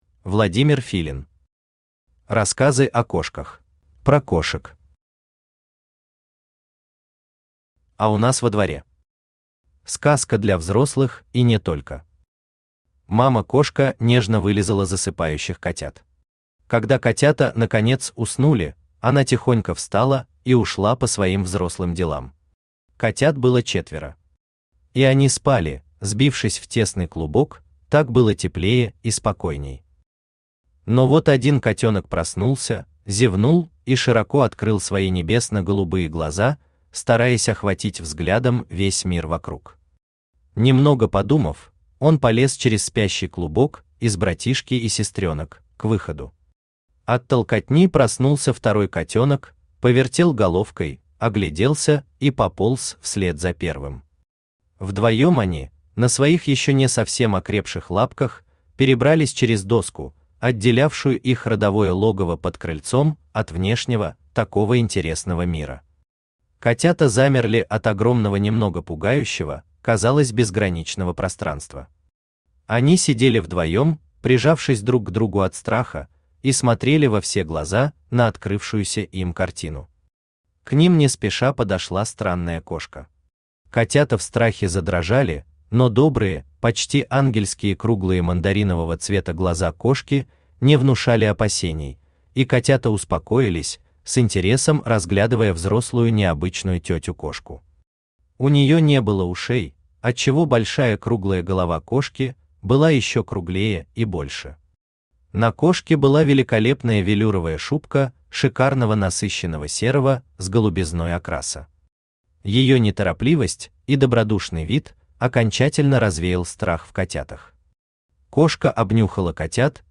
Аудиокнига Рассказы о кошках | Библиотека аудиокниг
Aудиокнига Рассказы о кошках Автор Владимир Иванович Филин Читает аудиокнигу Авточтец ЛитРес.